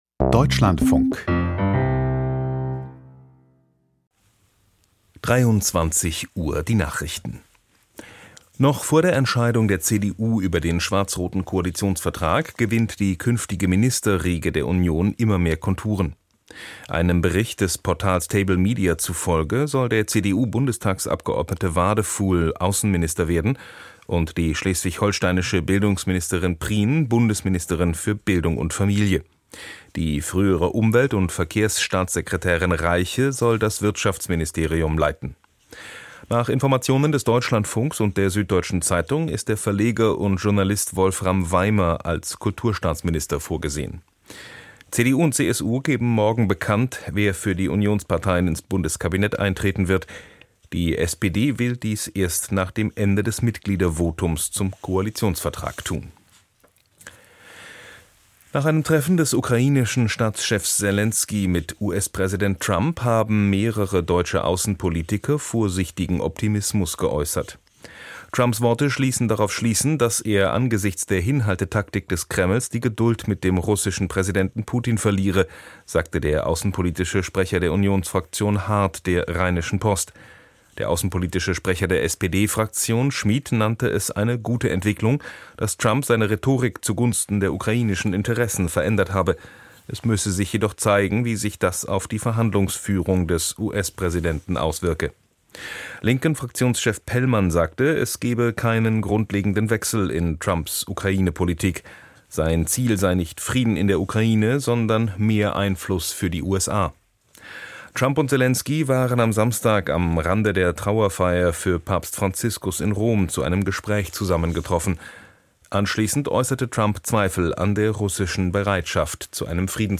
Die Deutschlandfunk-Nachrichten vom 27.04.2025, 23:00 Uhr